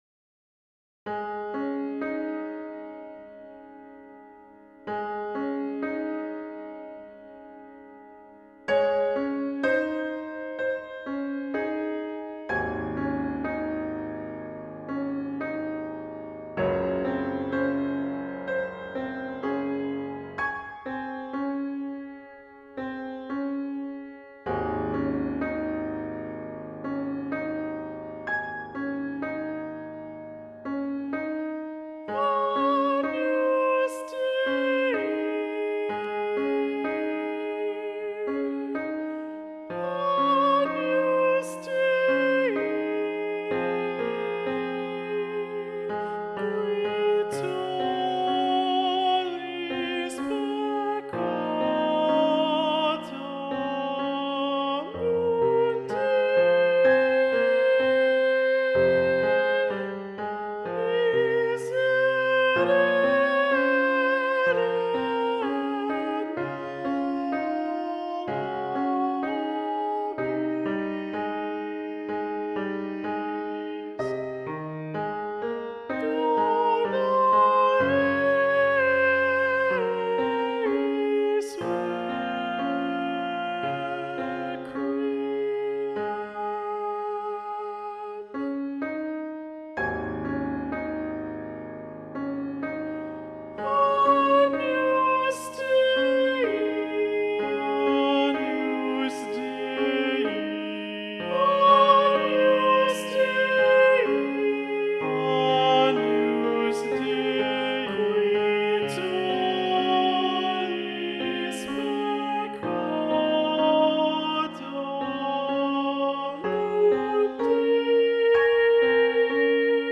Agnus Dei - Balances Voices Balanced Voices